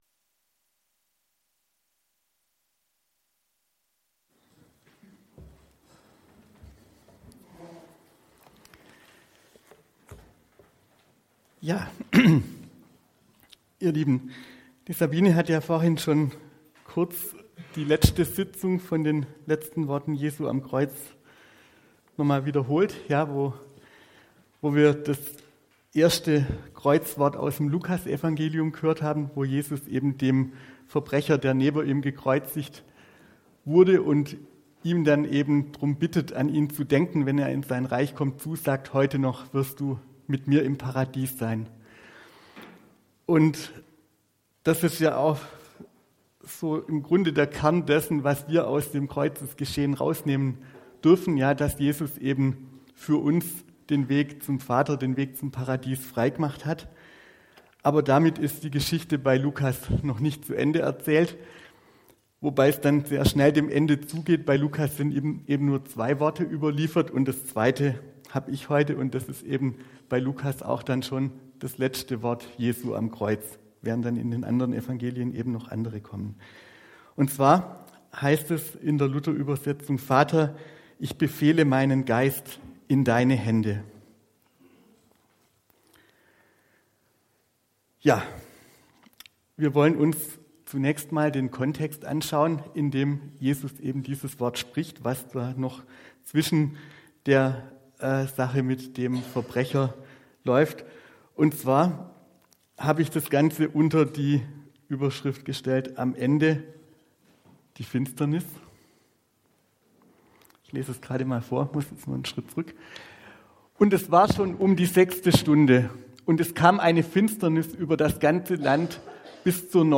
Predigt 16.03.2025 - SV Langenau